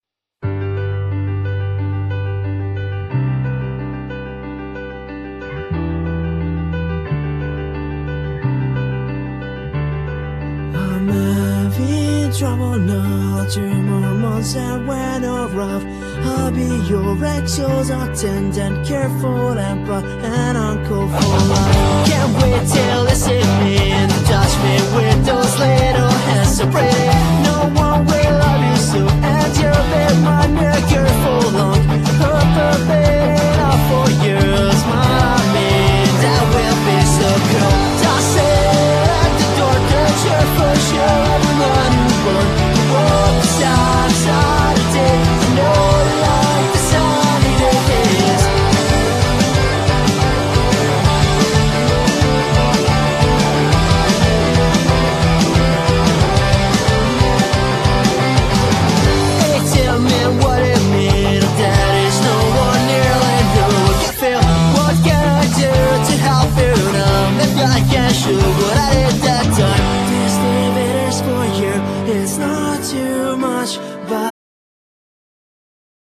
Genere : Pop
power-pop